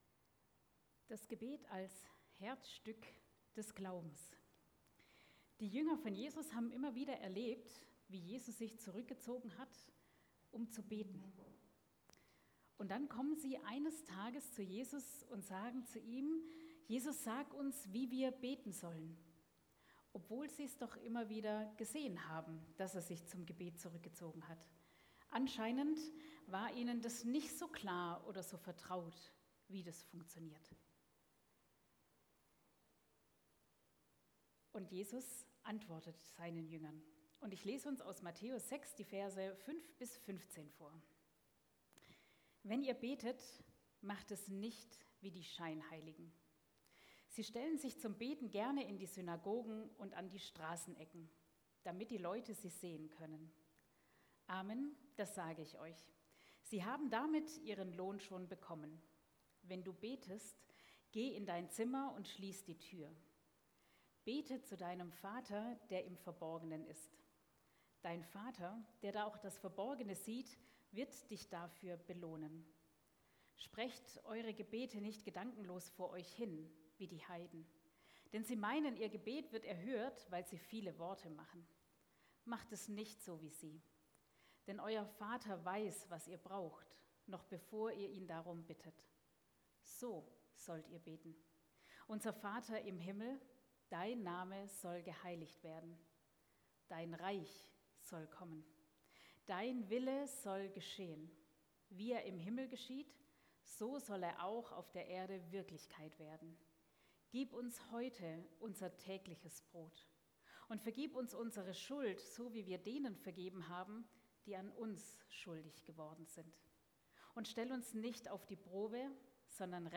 Gottesdienst | Stadtmission Ludwigshafen
Predigt vom 29. Juni 2025